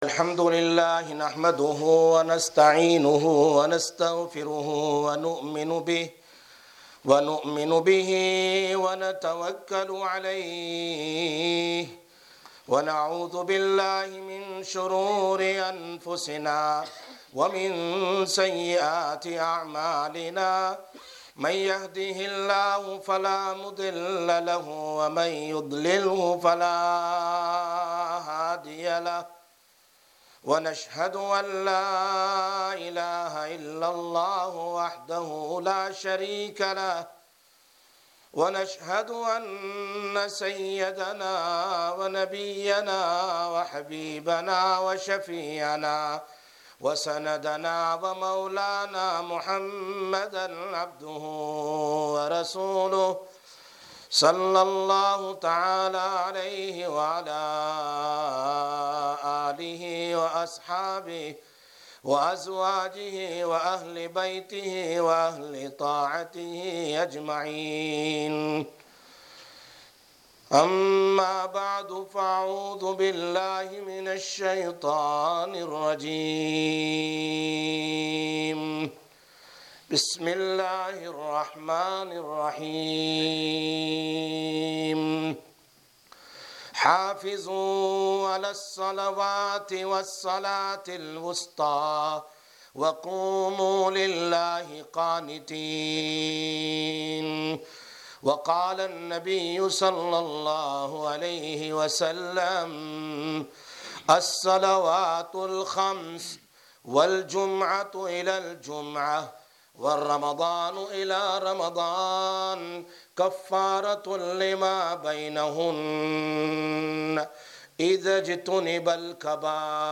30/08/19 Jumma Bayan, Masjid Quba